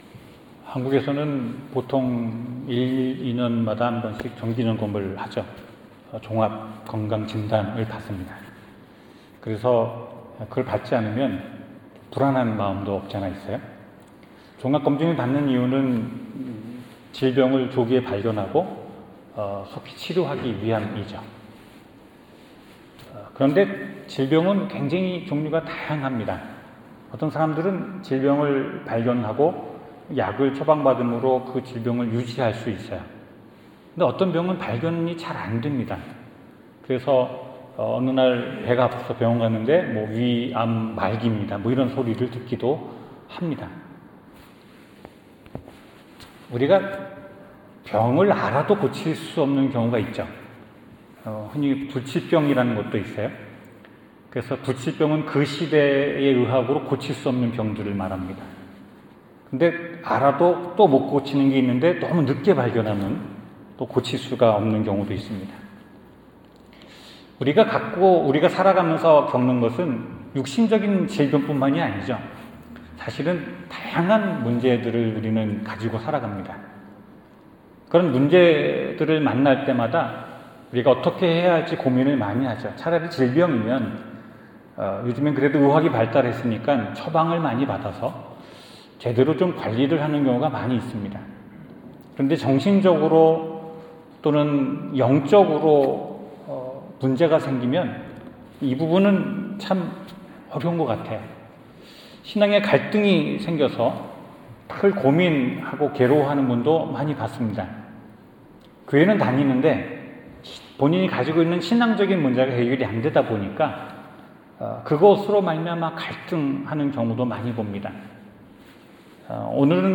성경 : 마가복음 5장 25-34절 설교